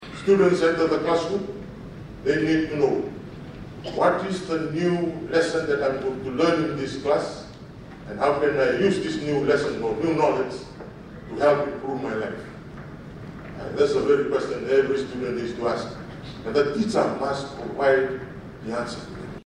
While presenting at the Principals Conference, Ministry of Education Deputy Secretary for Primary and Secondary Education, Timoci Bure emphasizes that this approach is pivotal in ensuring the continuous improvement of the education system.